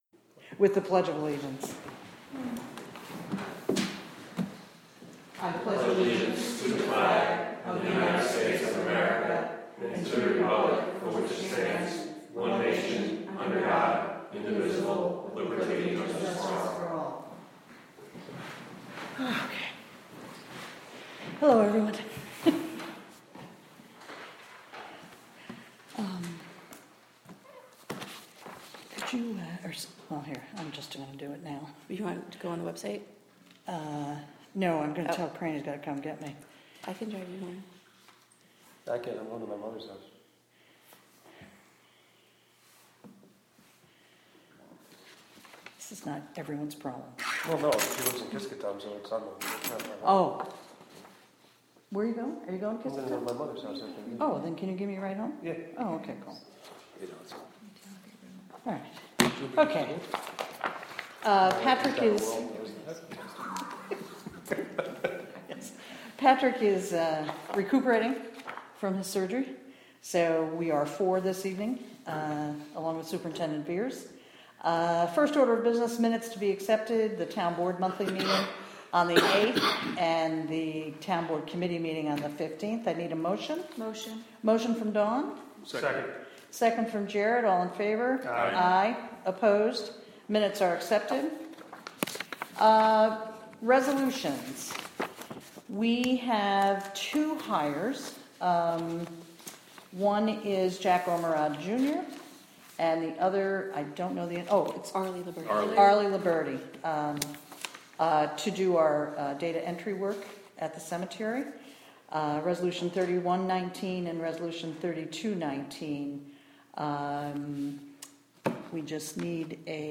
Audio Feature: June 4 Catskill Town Meeting Jun 05, 2019 12:01 am Catskill Town Hall 439 Main Street locations Catskill Town Hall Click here to download or play the June 4, 2019 Catskill Town Board meeting, a recording from a live webstream made by the town in conjunction with WGXC.